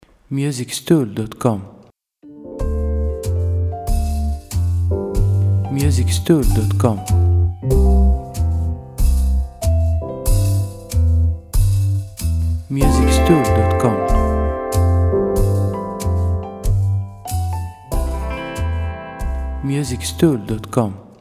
• Type : Instrumental / Audio Track
• Bpm : Adagio
• Genre : Jazz / Swing